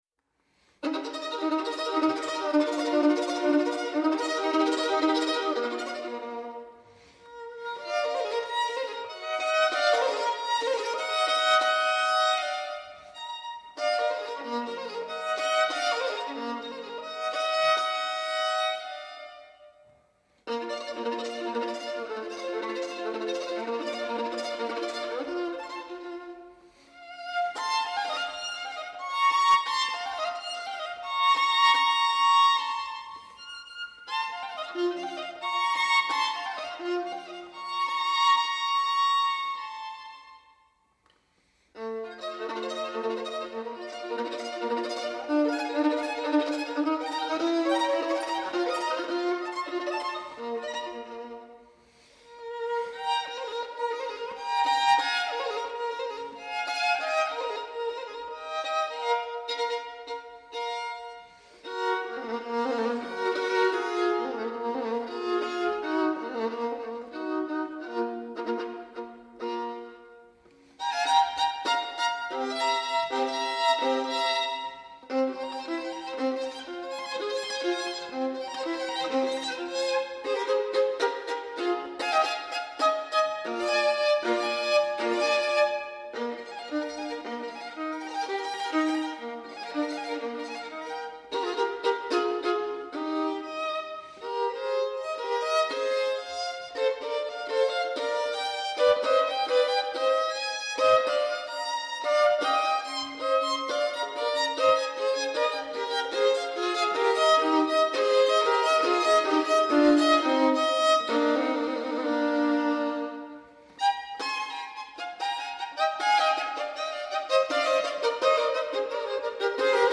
Incontro con Sergey Malov